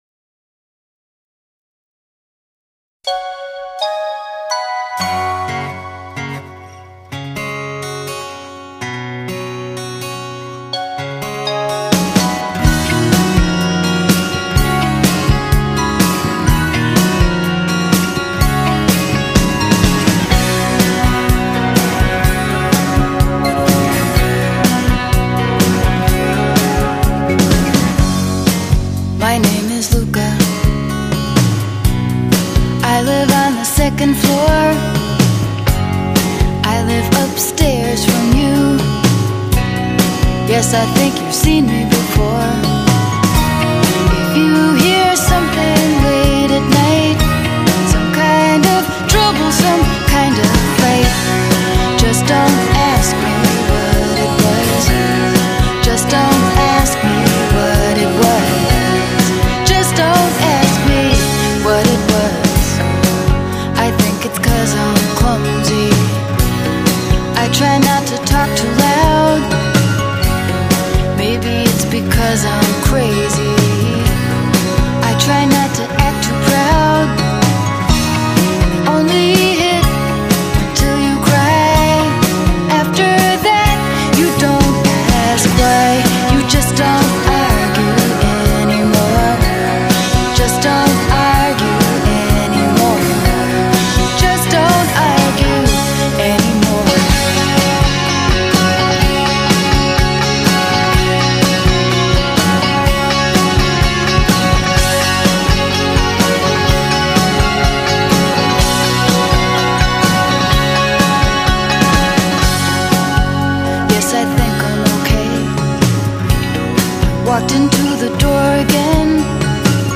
只是以她真挚平实的嗓音娓娓道来